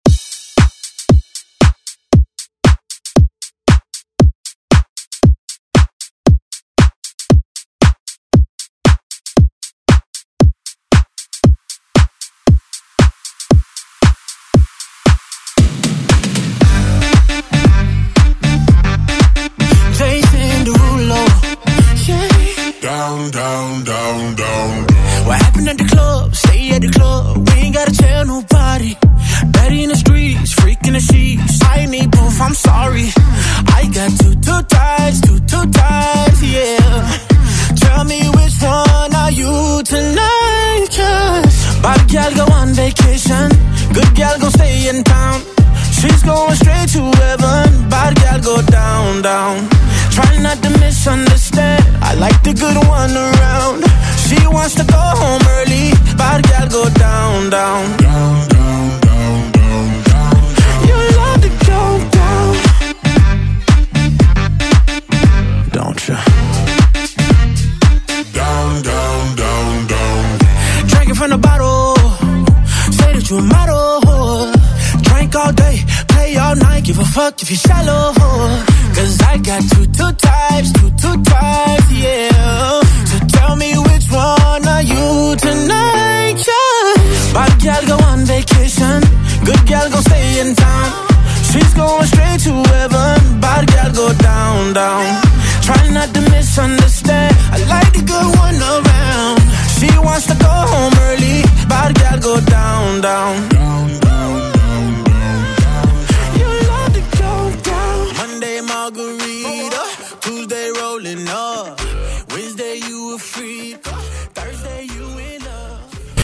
Electronic Dance Pop Music Extended ReDrum Dirty 116 bpm
BPM: 116 Time